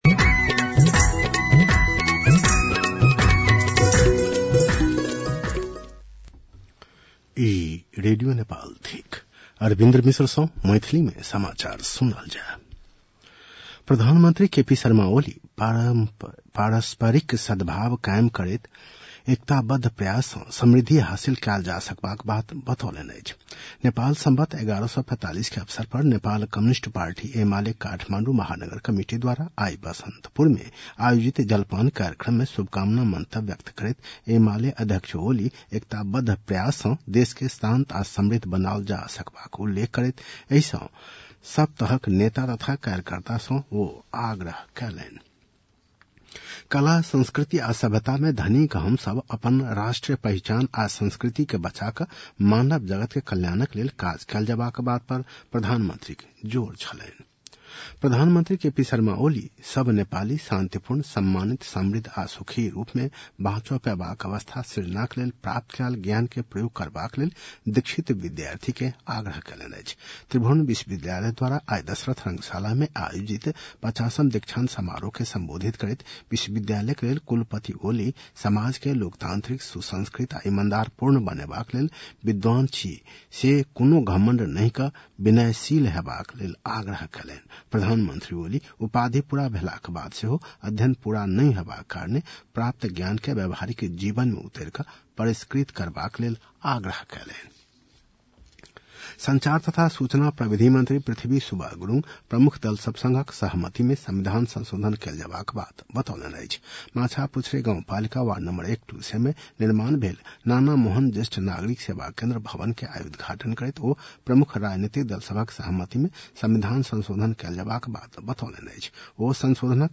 मैथिली भाषामा समाचार : १४ पुष , २०८१